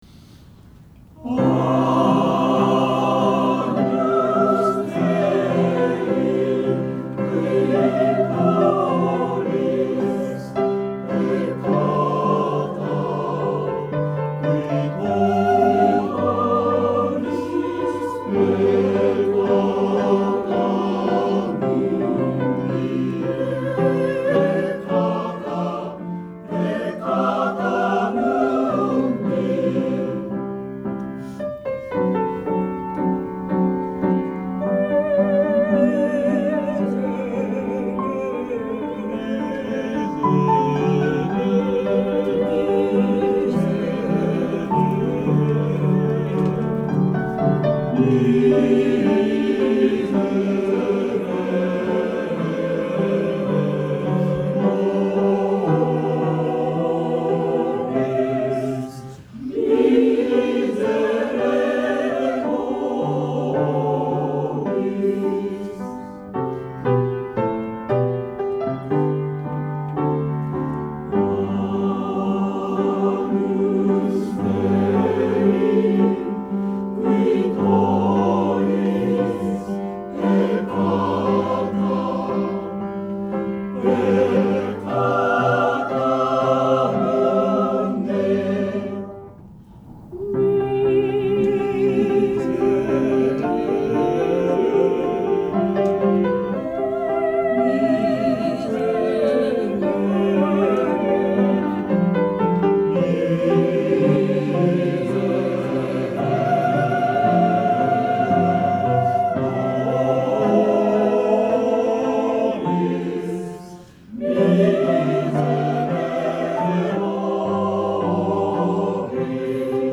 練習場所：アスピア明石北館　8階学習室801A・B（明石市）
出席者：31名（sop13、alt8、ten5、bass5）
・Agnus Dei　♪=96で　58小節ベースはレガートで　59小節フォルテからピアノ　「pa-cem」の「m」きれいに入れる　63小節ベースはフォルテで出るのでその前は早めにブレス　p29冒頭♪=66で　15小節ベースの入りはっきりと　39小節～のｐ意識しすぎなくて（音量）よい　→通し